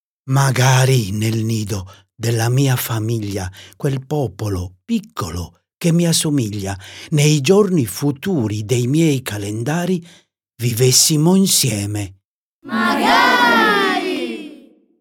La sequenza completa e continua delle 23 quartine, coi loro cori a responsorio, è offerta in coda al libro con un QR-code.